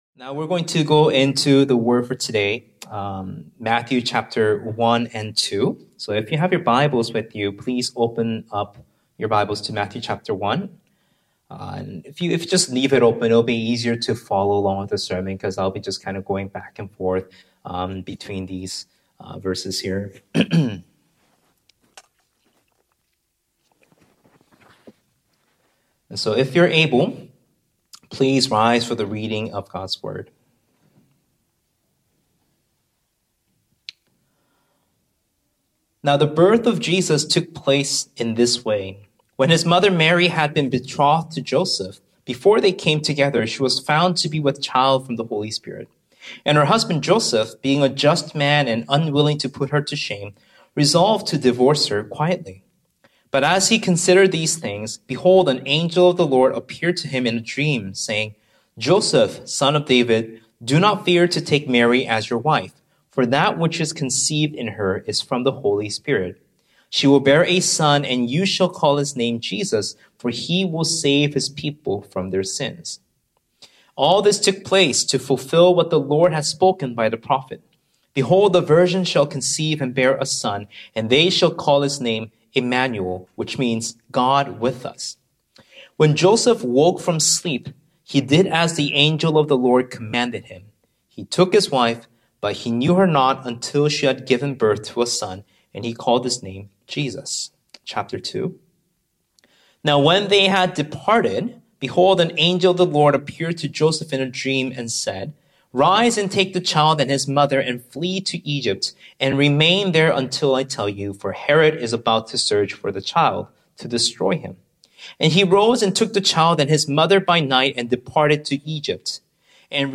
Sermons | Sonflower Community Church